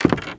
High Quality Footsteps
Wood, Creaky
STEPS Wood, Creaky, Walk 17.wav